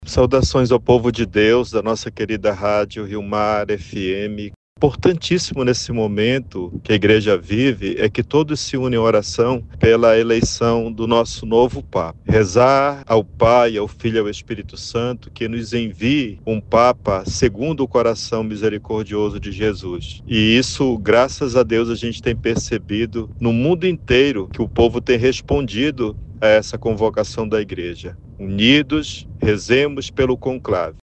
O bispo auxiliar de Manaus, Dom Hudson Ribeiro, dirige-se aos fiéis e pede orações de toda a comunidade católica da capital amazonense pelo início do conclave que escolherá o novo líder da Igreja.